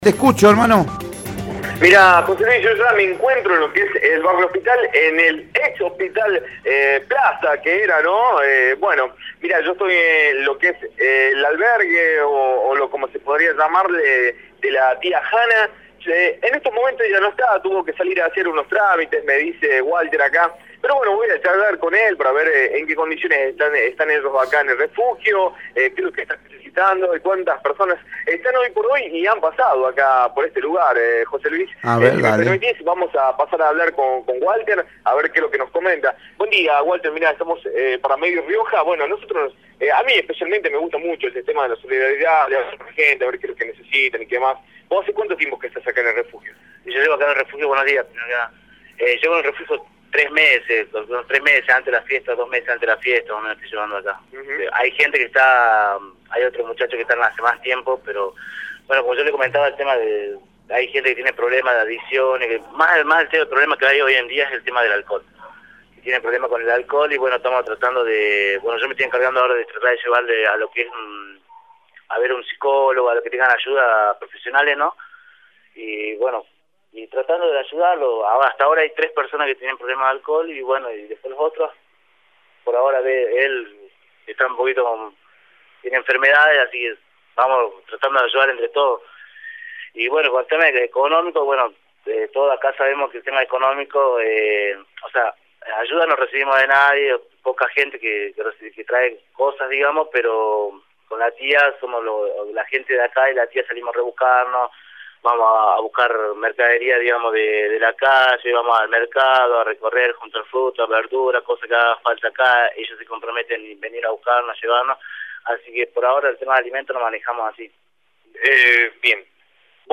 Informe de Radio Rioja